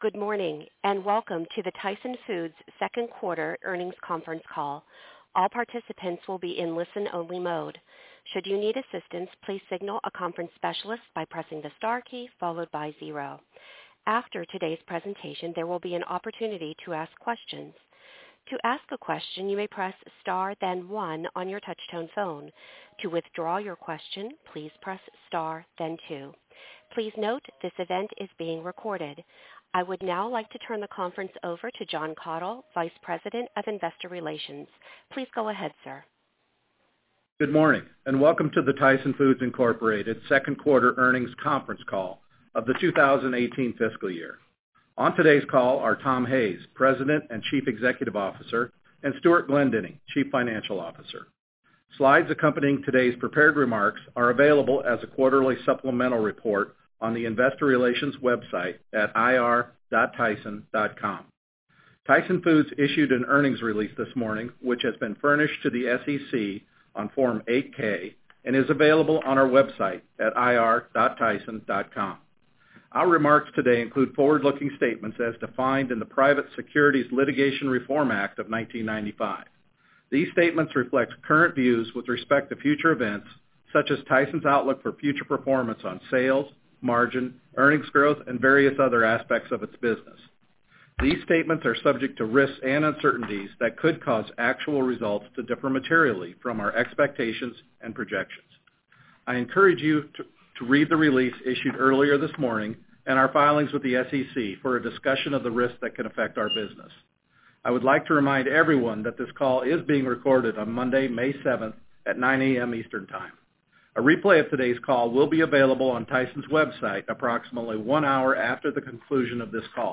Tyson Foods Inc. - Q2 2018 Tyson Foods Earnings Conference Call